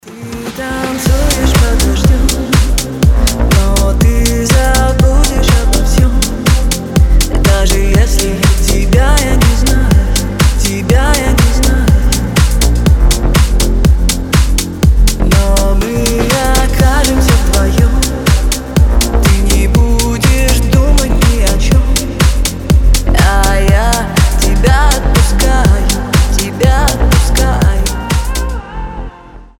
• Качество: 320, Stereo
deep house
женский голос
расслабляющие
Vocal House